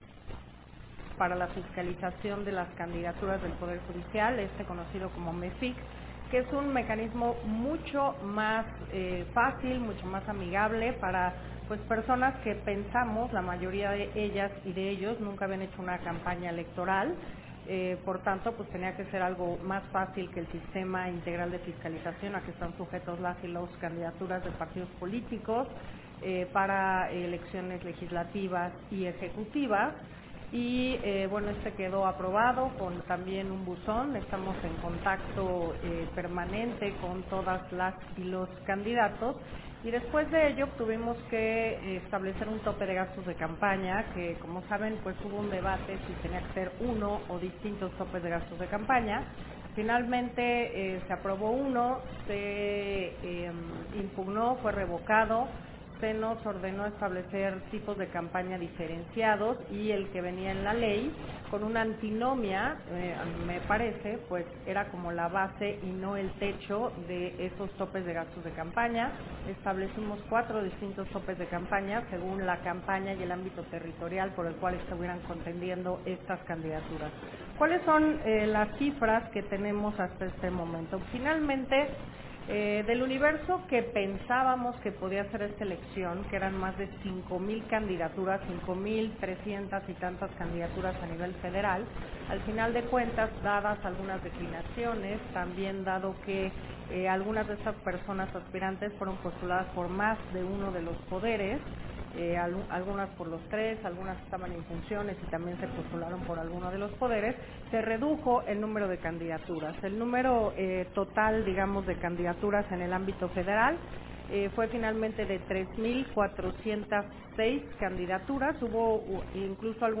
020625-AUDIO-CONFERENCIA-SOBRE-FISCALIZACION